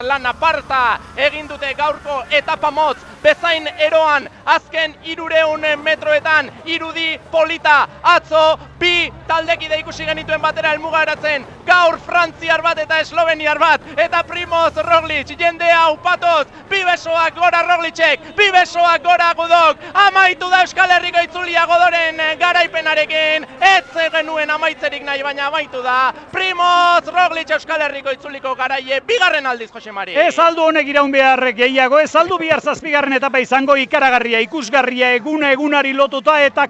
Itzuliko azken metroak Euskadi Irratian